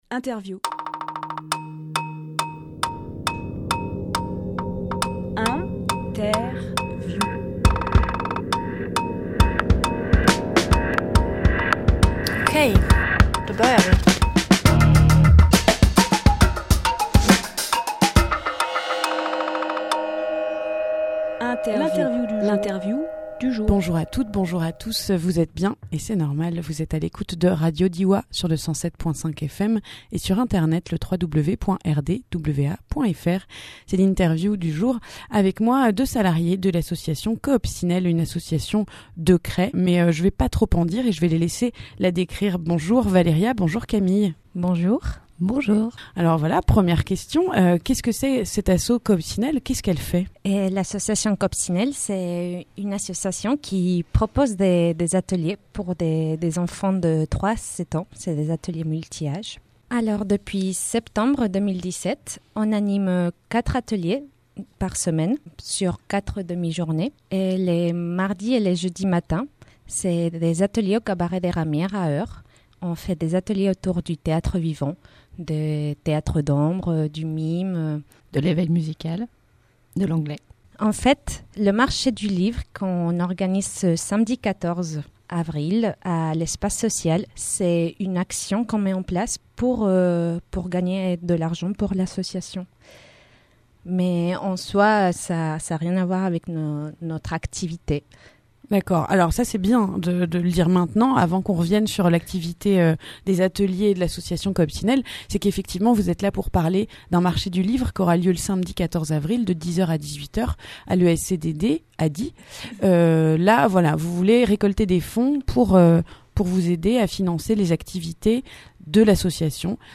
Emission - Interview L’association Coopcinelle Publié le 11 avril 2018 Partager sur…
Lieu : Studio RDWA